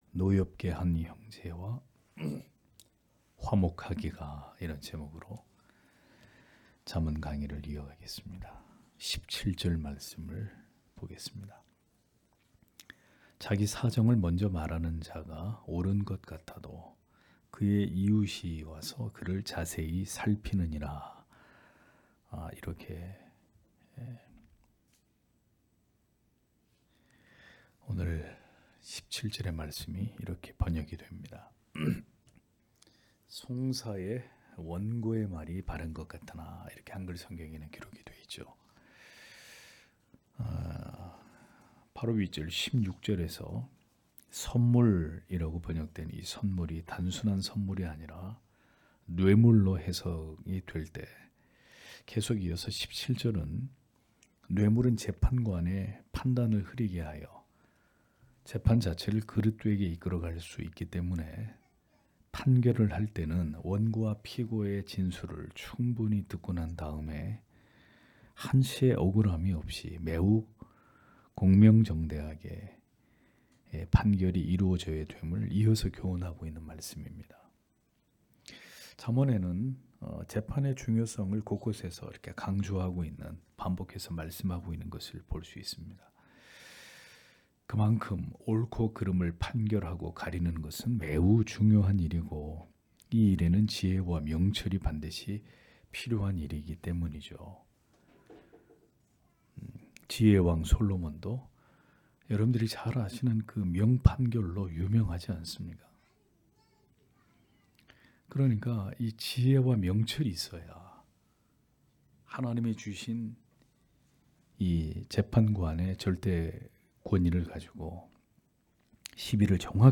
수요기도회 - [잠언 강해 109] 노엽게 한 형제와 화목하기가 (잠 18장 17-19절)